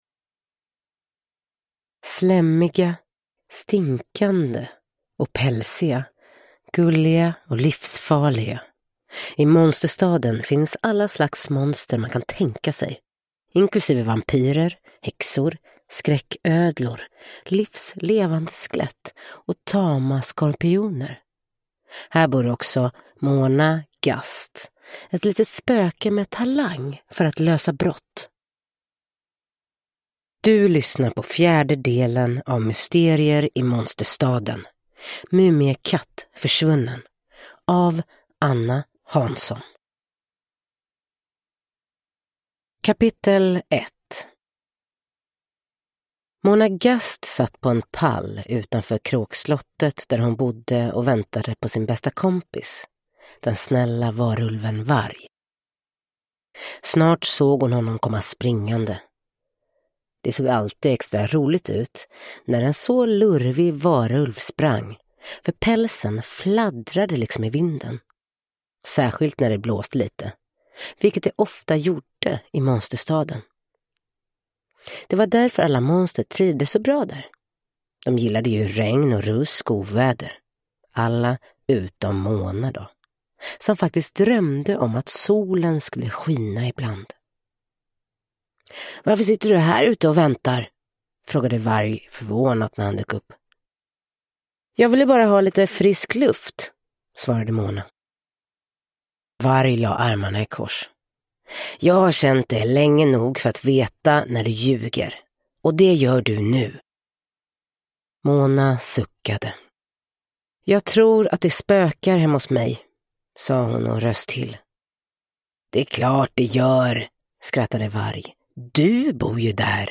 Mumiekatt försvunnen! – Ljudbok